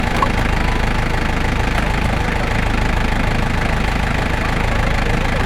모터.mp3